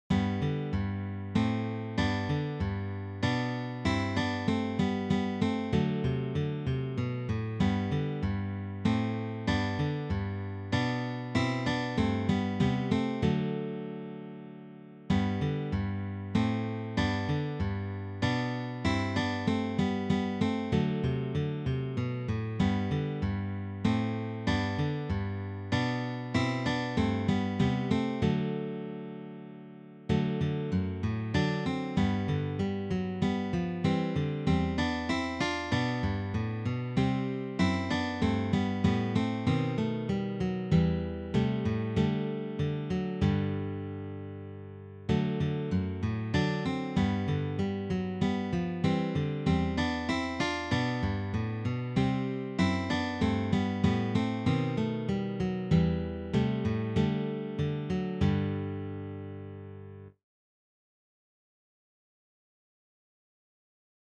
arranged for three guitars
This Baroque selection is arranged for guitar trio.